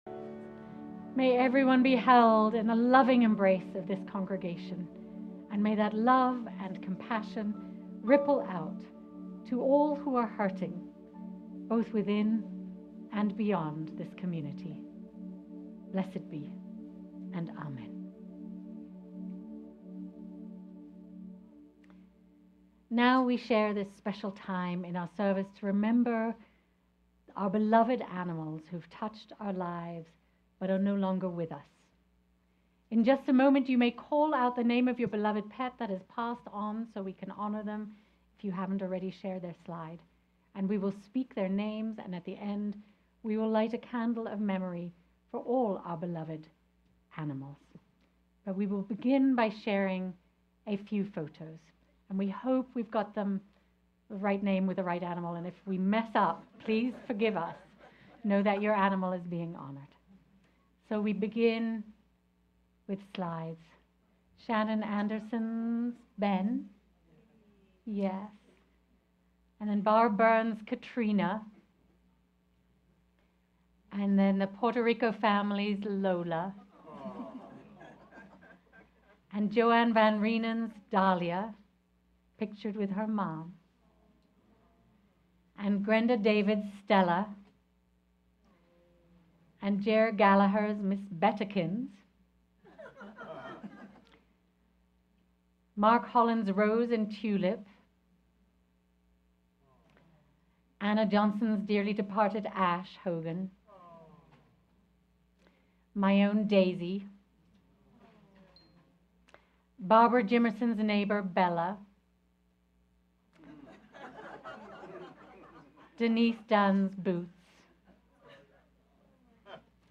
Life is Better with You: Animal Blessing Service